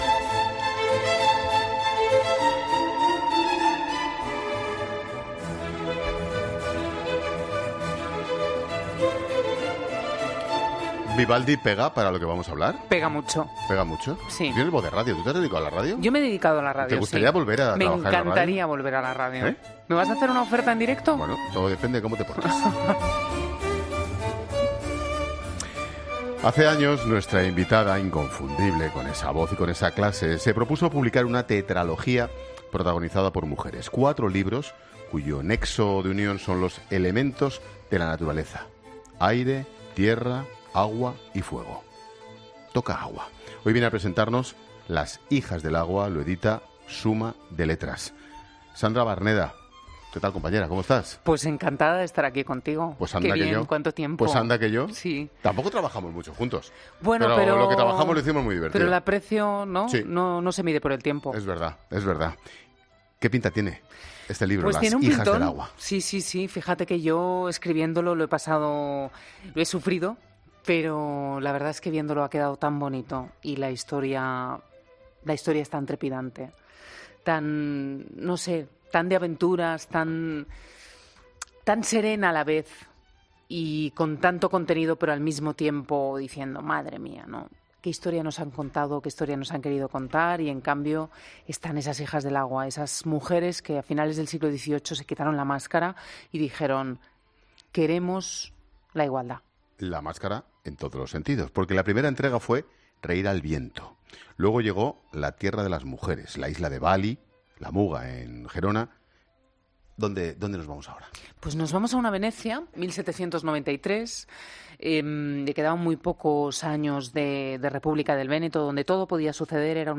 Ángel Expósito entrevista a la periodista y escritora, un día antes de la presentación de su nuevo libro